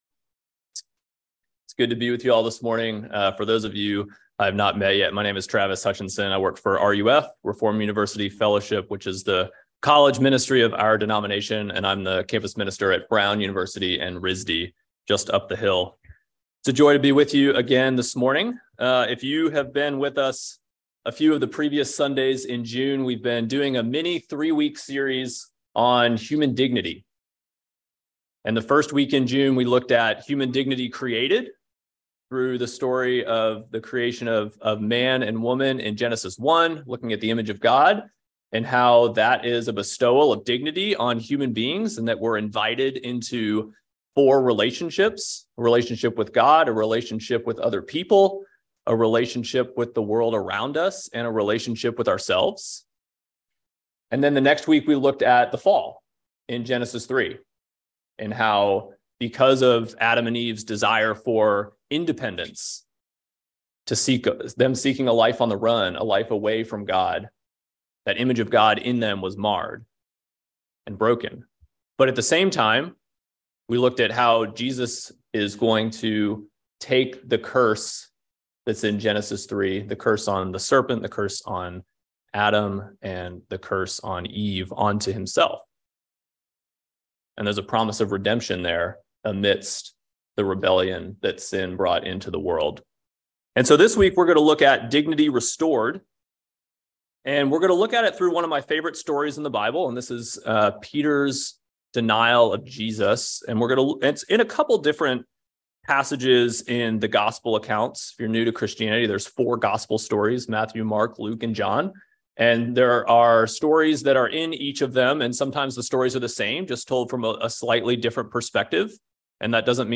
by Trinity Presbyterian Church | Jul 17, 2023 | Sermon